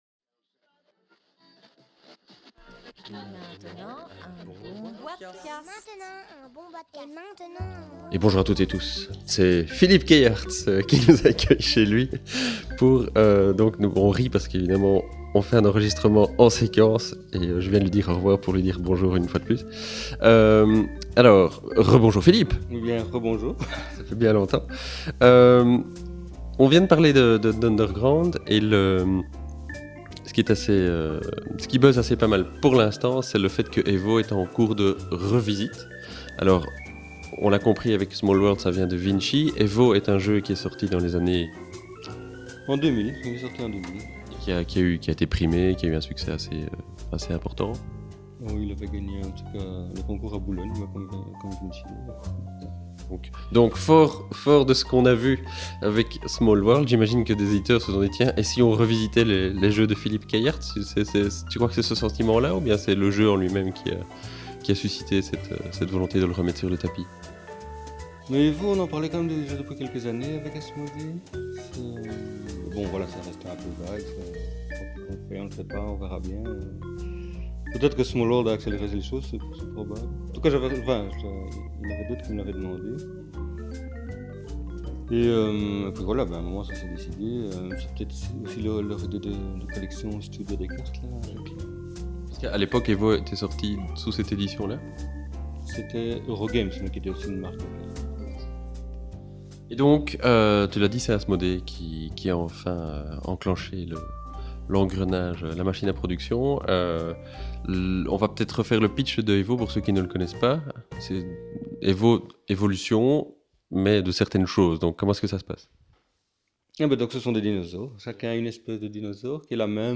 c’est ce dont il nous parle dans ce boitecast enregistré chez lui autour des sa multitude de jeux de société